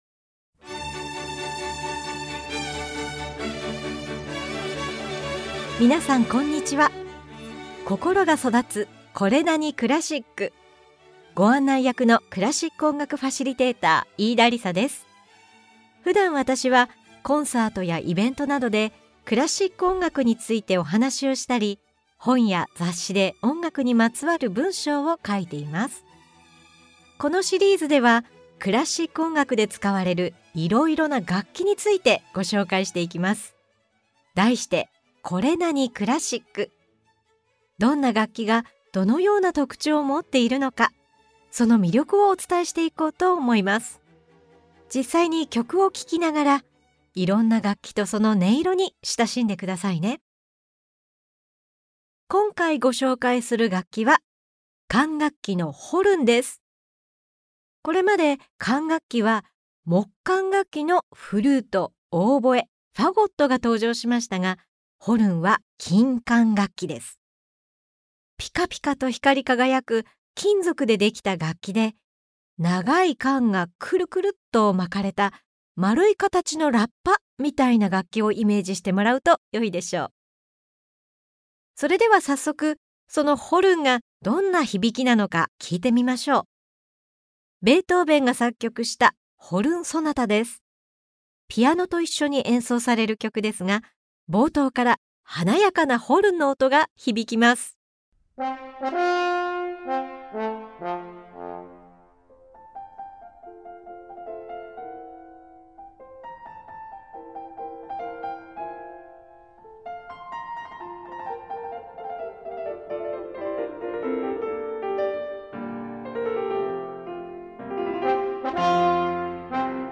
Vol.8では、ピカピカと光り輝く長い管がくるくると巻かれた丸い形の金管楽器「ホルン」に注目！冒頭から華やかなホルンの音が響くベートーヴェンの「ホルン・ソナタ」、ホルンの音色が雄大な自然の光景を表現した「アルプス交響曲」、オーケストラの中でホルンが主役となって歌うチャイコフスキーの交響曲などを紹介しながら、ホルンのさまざまな音色とその魅力を紹介します！
[オーディオブック]